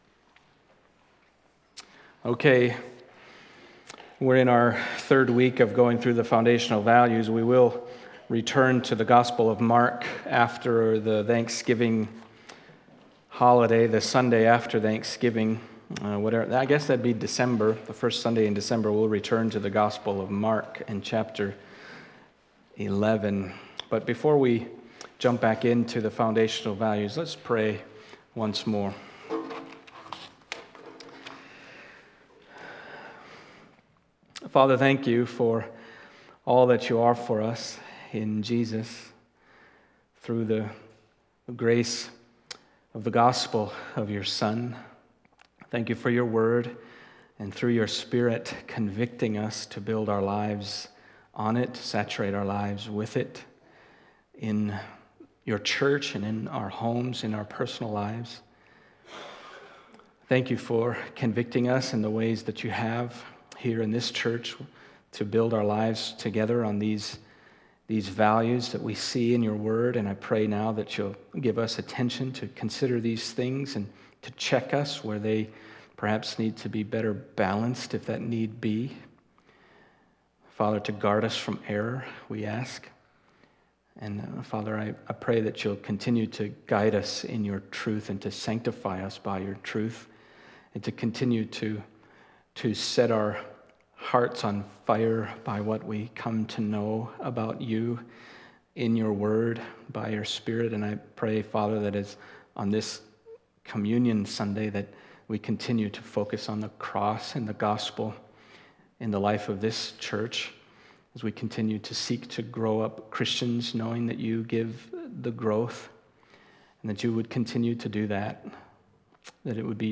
Foundational Values Service Type: Sunday Morning 5.